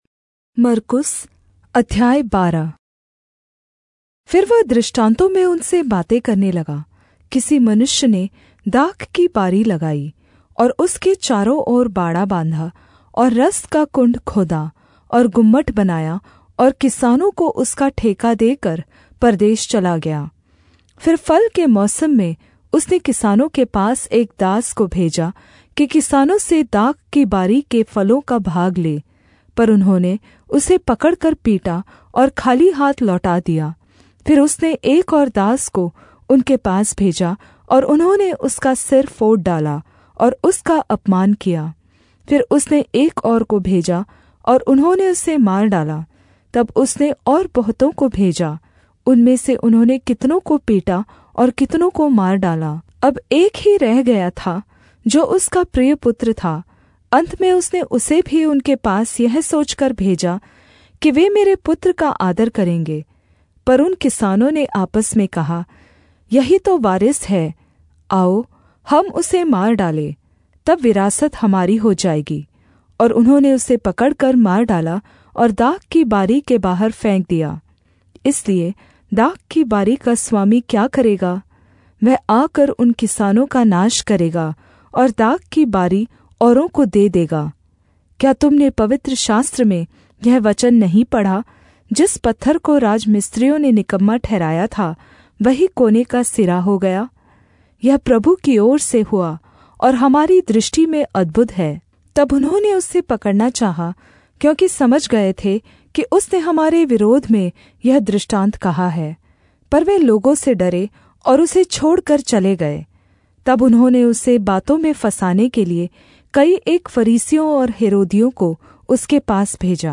Hindi Audio Bible - Mark 4 in Irvhi bible version